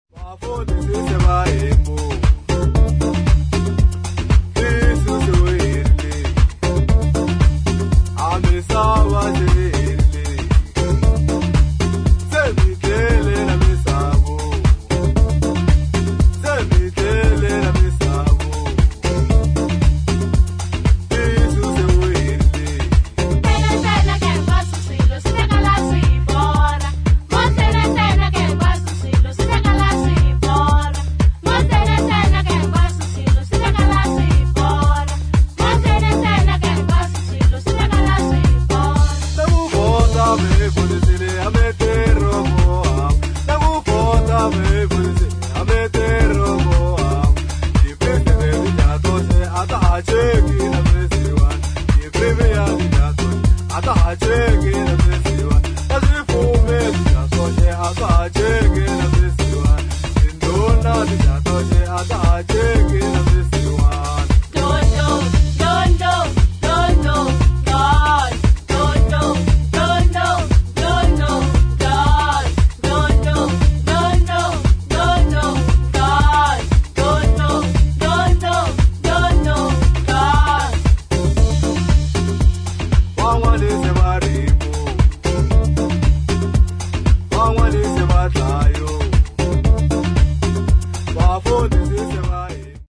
[ DISCO / EDIT ]
ソウルフルなディスコ・グルーヴとレアな南アフリカのファンクが絡むパーティー・チューン！